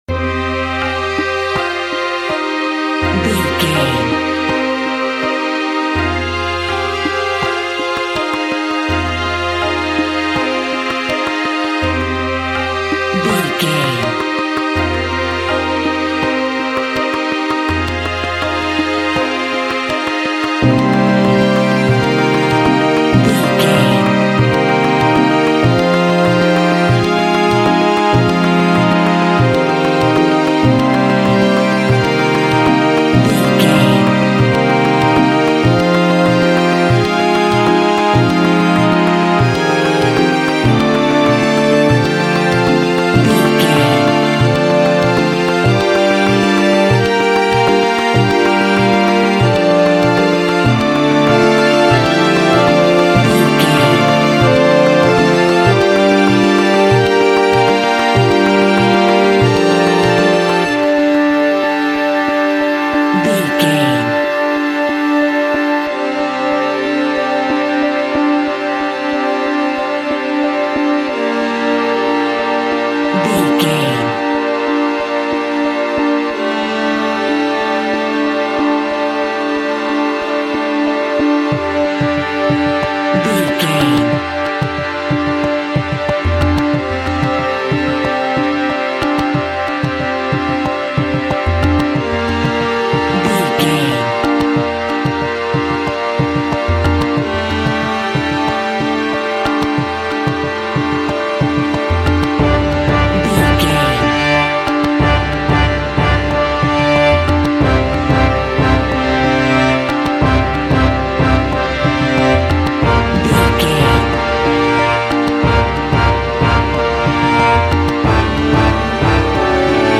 Aeolian/Minor
Exotic
World Music